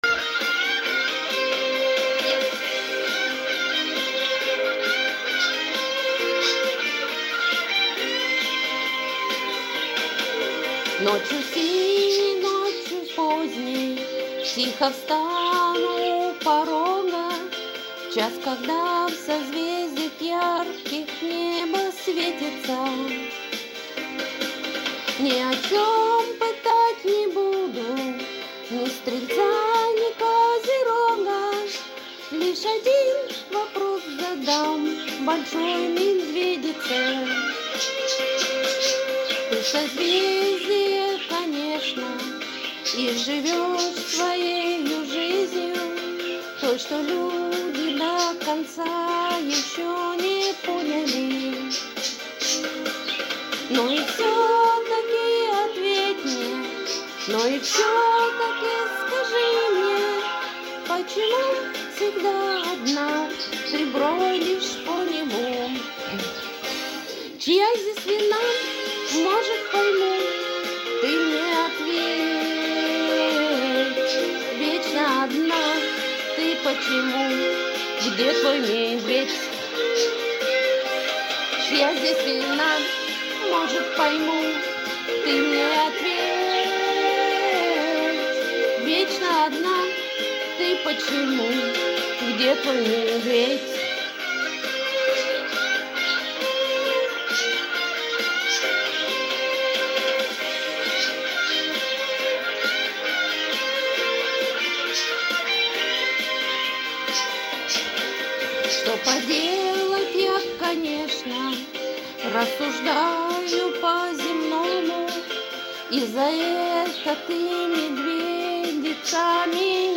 Ну, спою немного, с попугаями)
Со звуками природы) :cool:
:) а куда их девать? орут похлеще меня)
Такой нежный голосок.